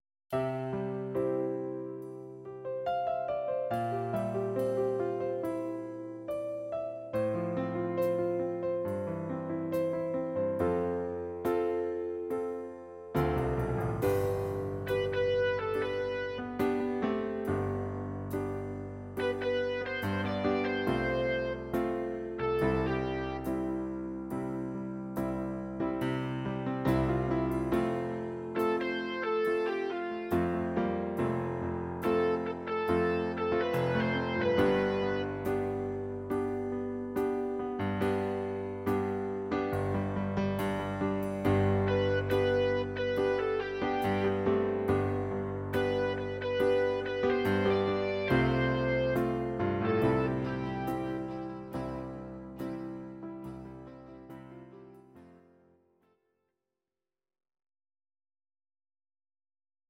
Audio Recordings based on Midi-files
Pop, Duets, 1990s